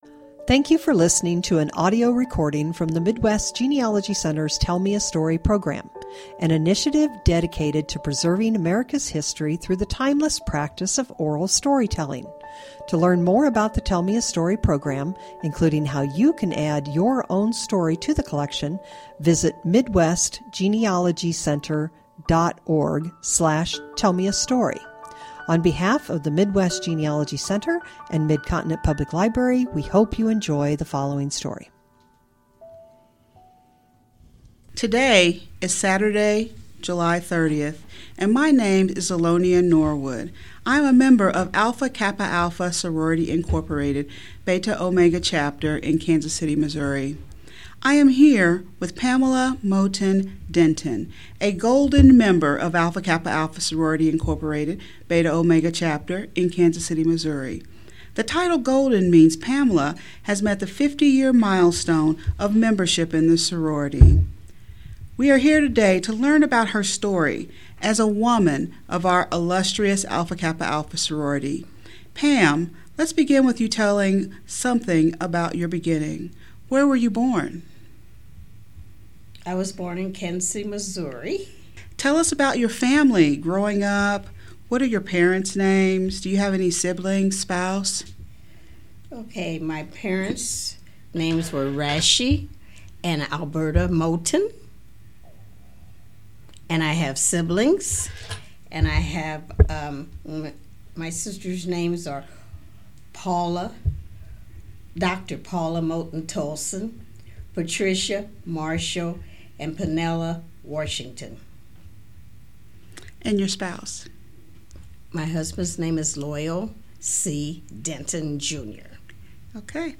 Oral history Genealogy Family history